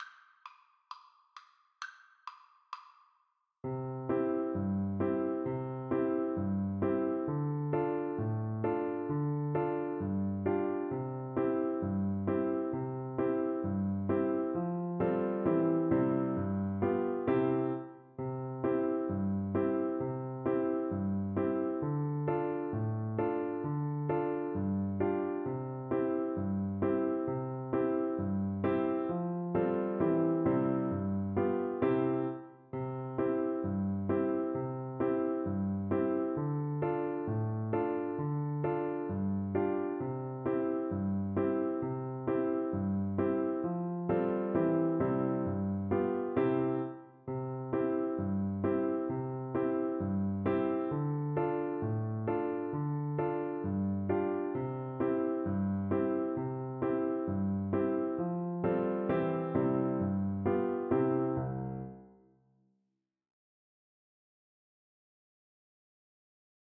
Flute
C major (Sounding Pitch) (View more C major Music for Flute )
4/4 (View more 4/4 Music)
Traditional (View more Traditional Flute Music)
Hornpipes for Flute